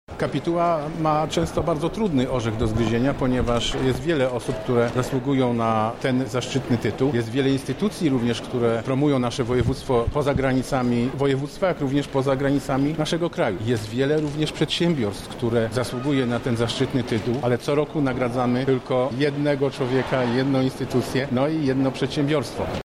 O tym czym kierowała się kapituła podczas przyznawania nagród mówi Sławomir Sosnowski, Marszałek Województwa Lubelskiego.